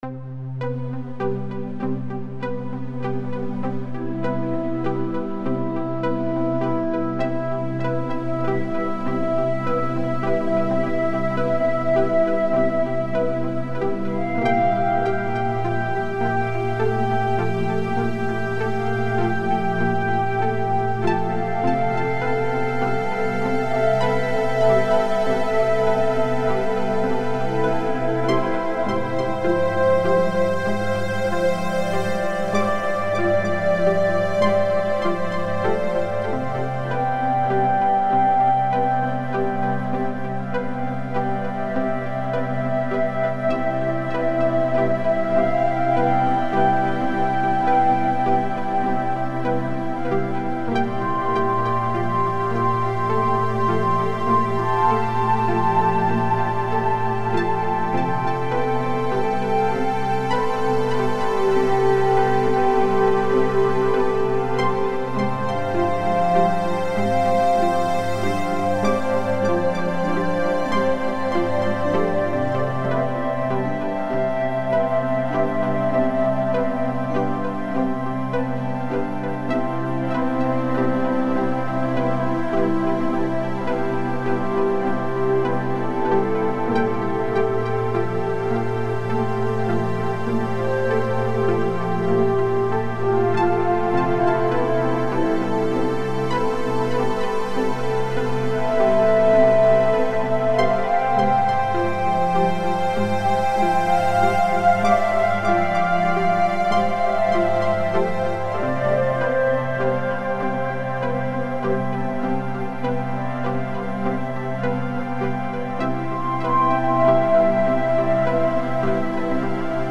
Thoughtful expressions through intelligent new age.